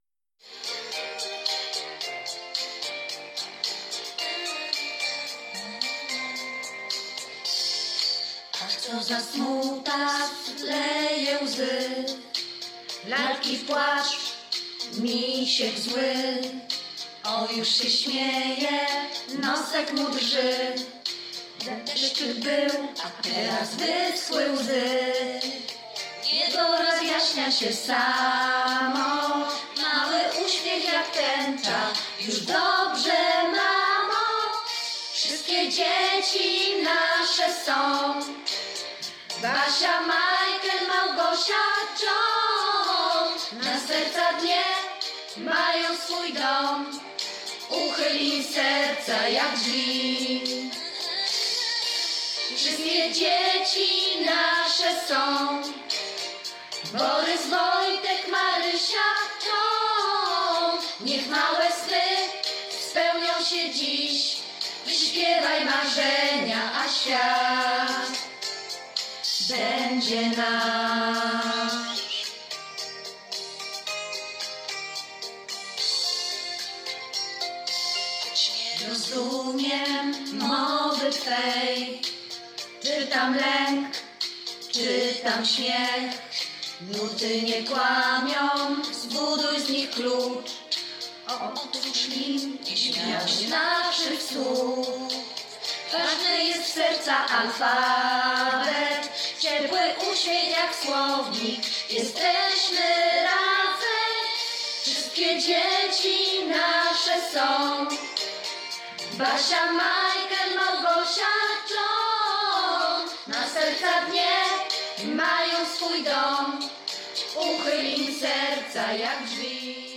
Z okazji Dnia Dziecka dla małych , i dużych … od tych jeszcze starszych :) taki oryginalny prezent i życzenia.
piosenka-z-życzeniami.mp3